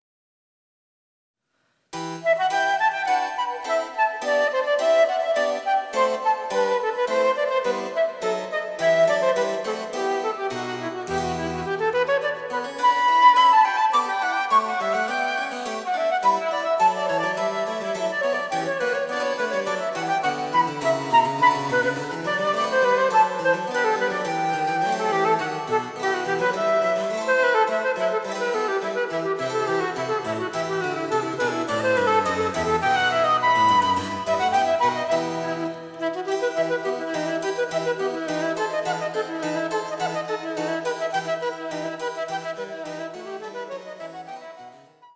■フルートによる演奏